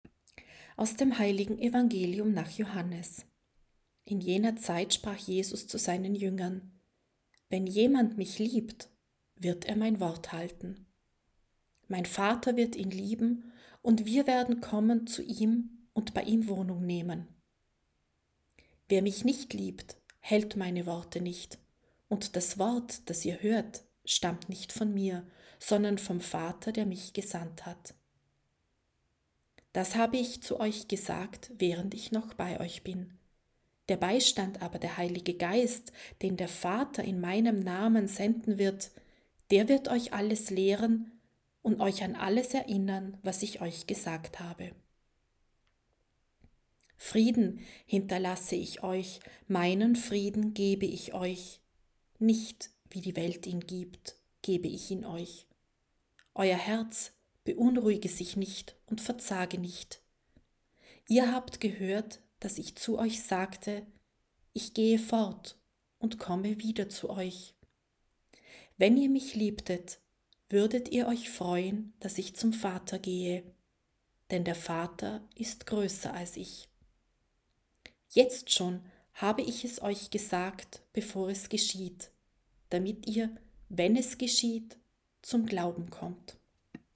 C-6.-Sonntag-d-Osterzeit-Evangelium.mp3